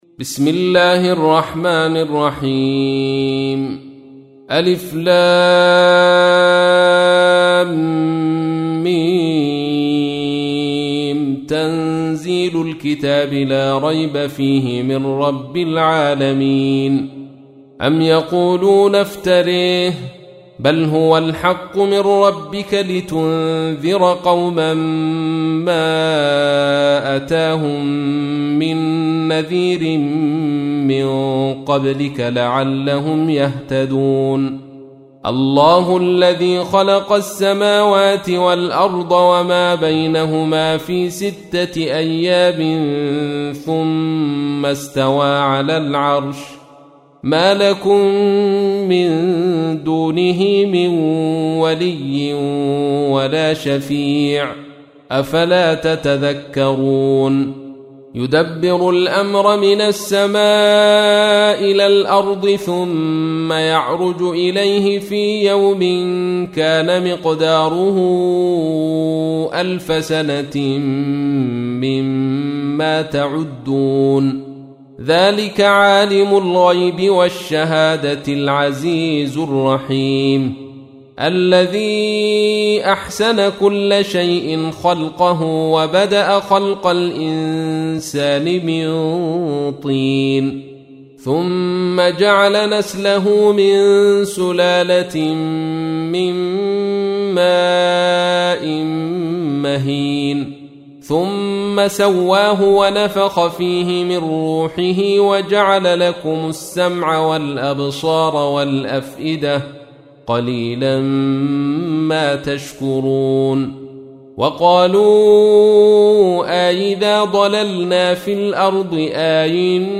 تحميل : 32. سورة السجدة / القارئ عبد الرشيد صوفي / القرآن الكريم / موقع يا حسين